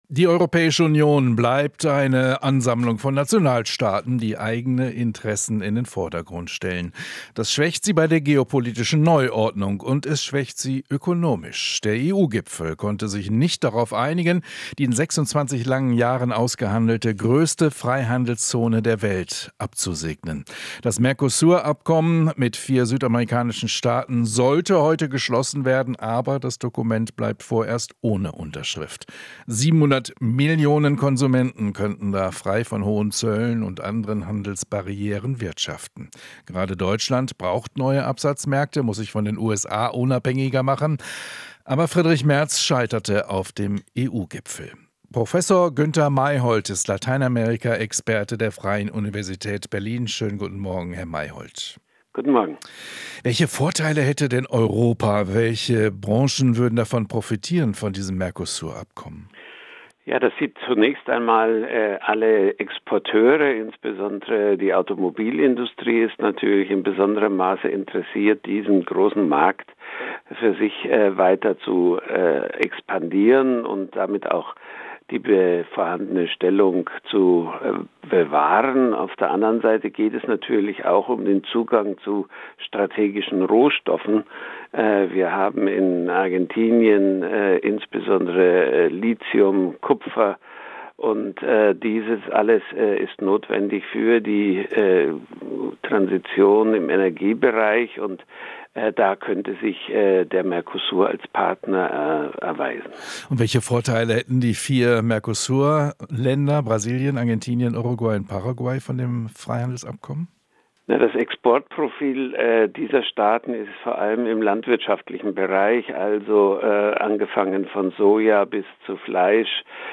Lateinamerika-Experte: Europa nimmt Südamerika nicht ernst
In Interviews, Beiträgen und Reportagen bilden wir ab, was in der Welt passiert, fragen nach den Hintergründen und suchen nach dem Warum.